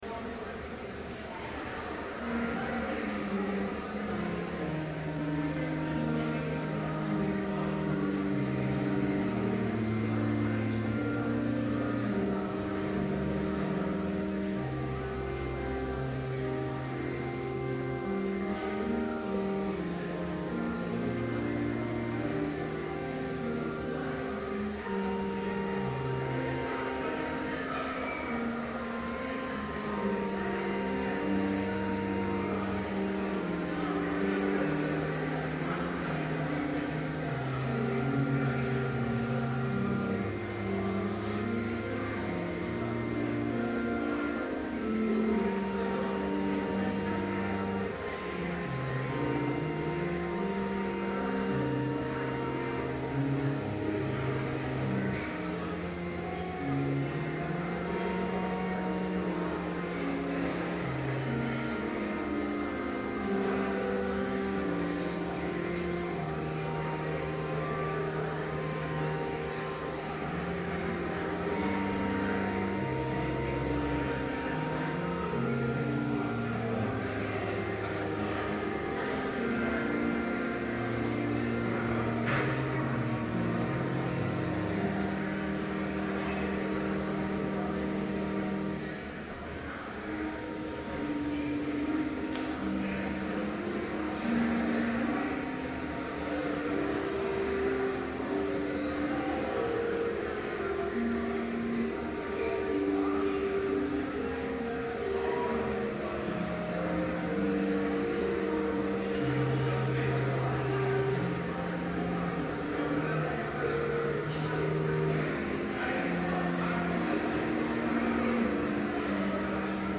Complete service audio for Chapel - February 1, 2012
Prelude Hymn 225, With Trembling Awe the Chosen Three Reading: Mathew 17:1-8 Homily Prayer Hymn 54, Beautiful Savior Benediction Postlude